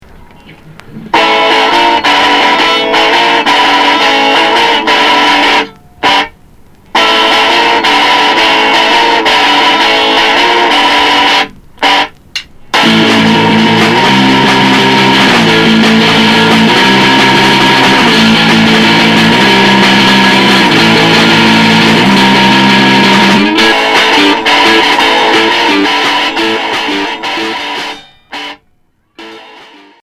Noisy pop